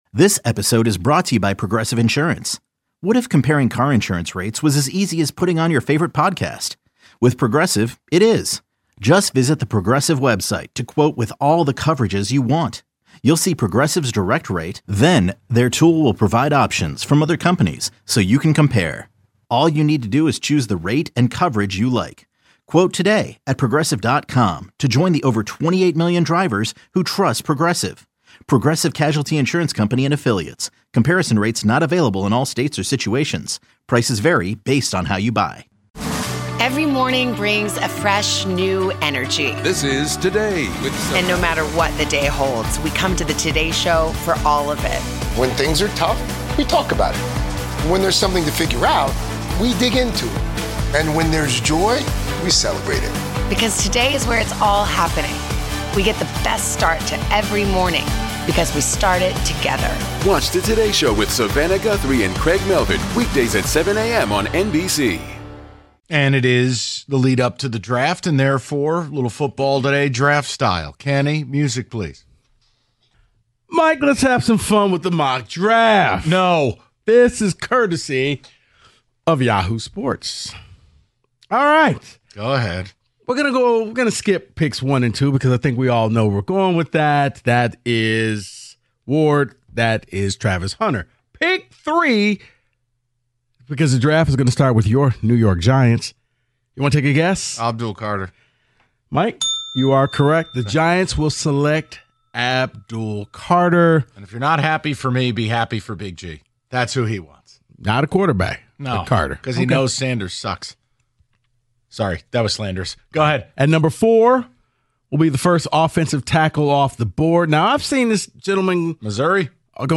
Then, they take more of your calls on the Jameson Williams conversation before discussing Shaq's accident on "Inside the NBA".